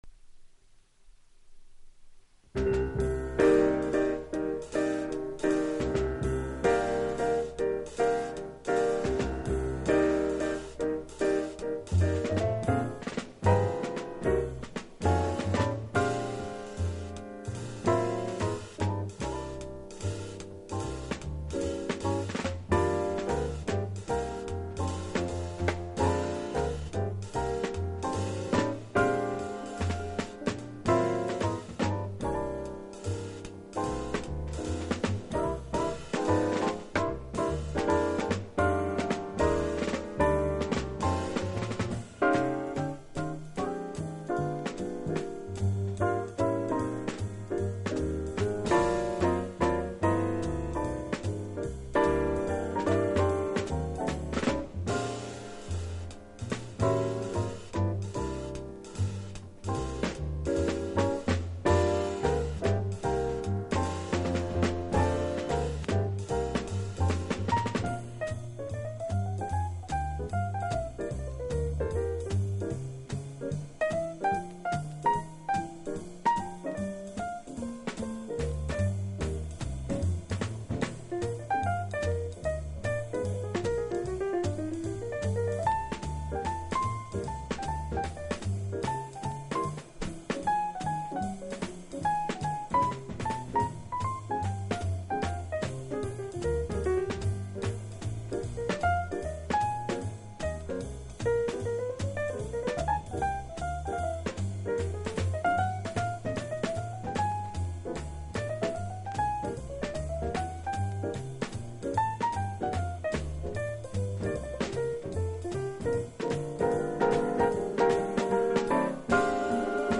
トリオもの名盤